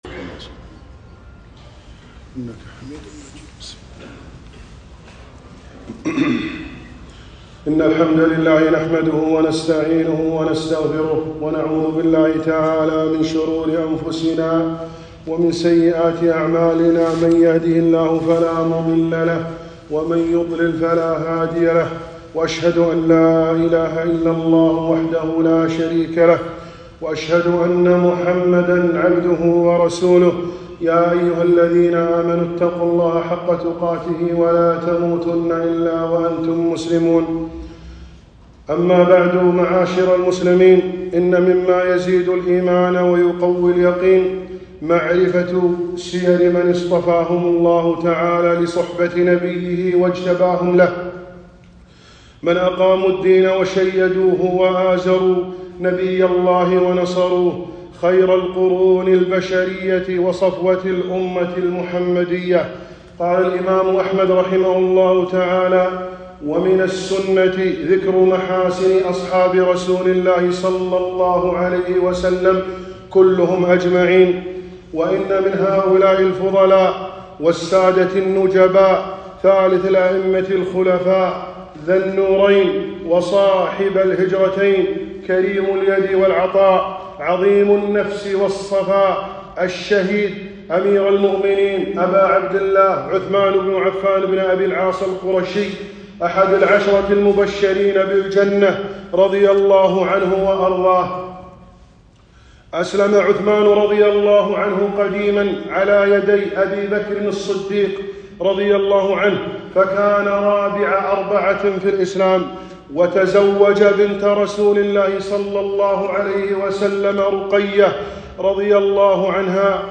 خطبة - فضائل عثمان بن عفان رضي الله عنه